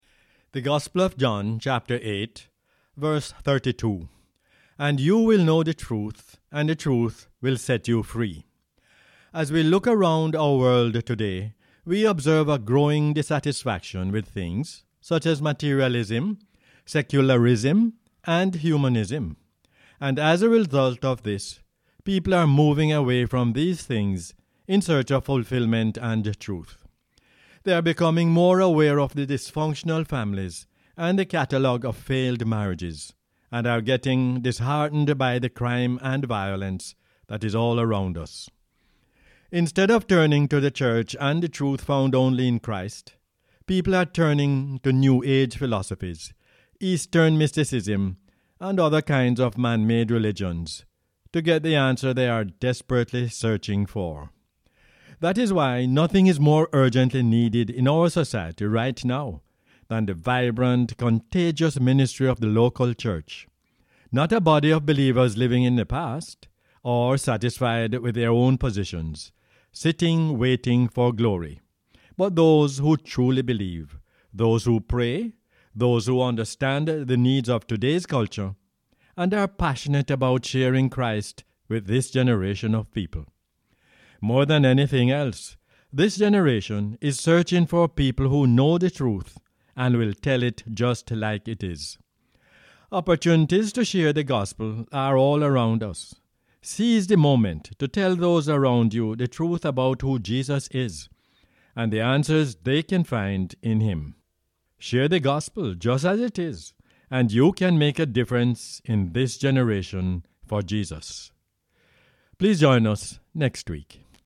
John 8:32 is the "Word For Jamaica" as aired on the radio on 1 April 2022.